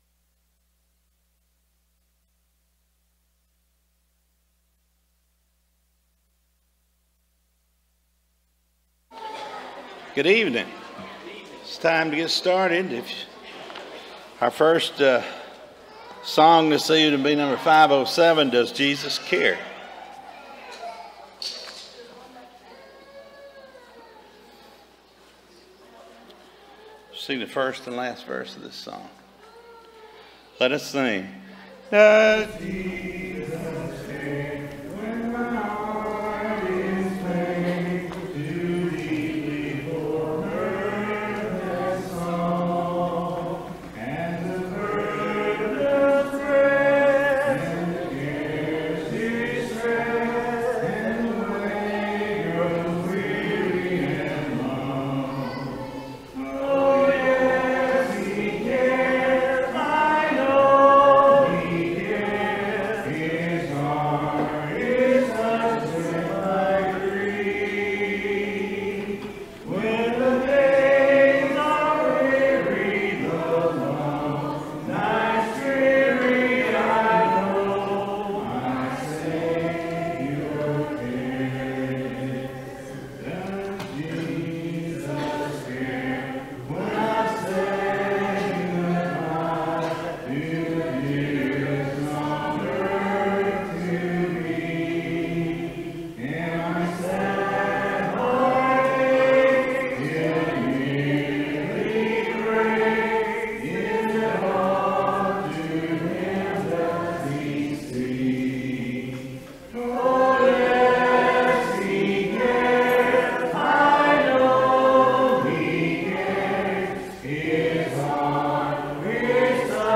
Revelation 3:15 Series: Sunday PM Service